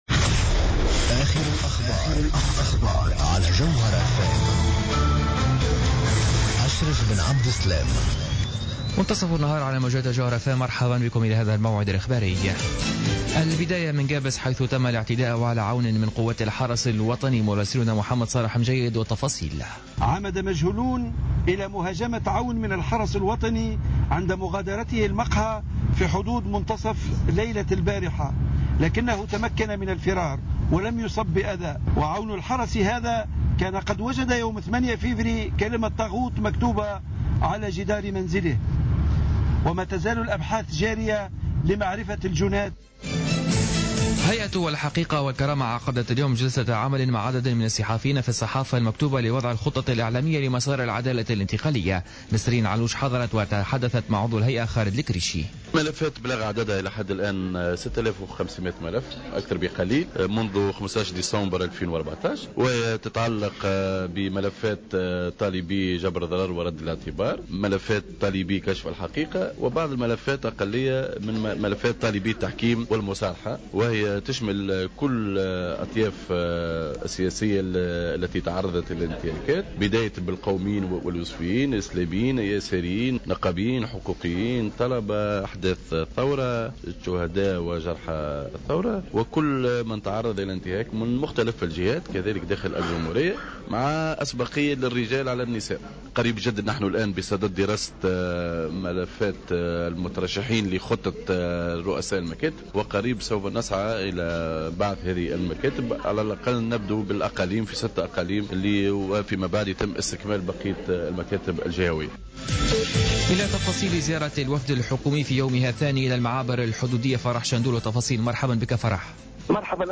نشرة أخبار منتصف النهار ليوم الخميس 12 فيفري 2015